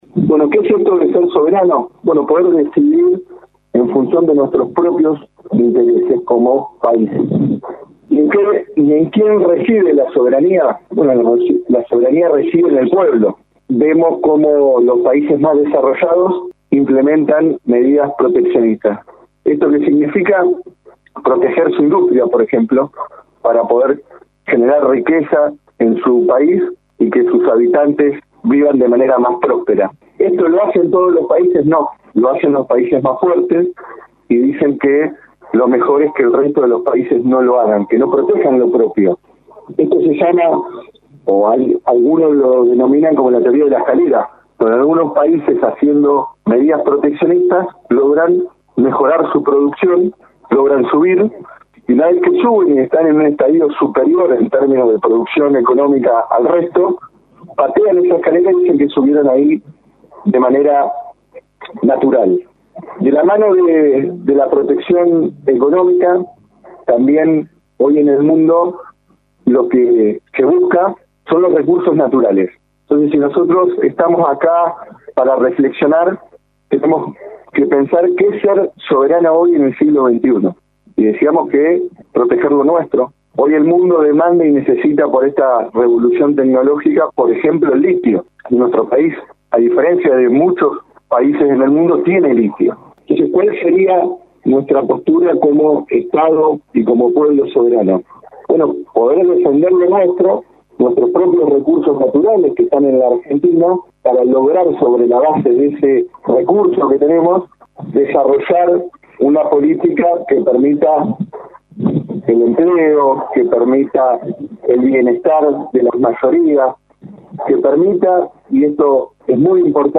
El encuentro se efectuó a primera hora de la mañana del miércoles 20.